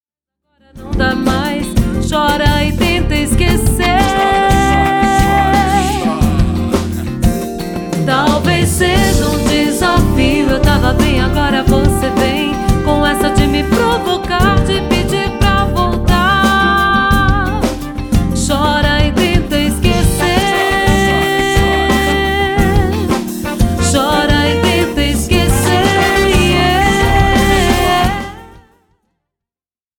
Violão
Genero:   Samba Rock / New Bossa / MPB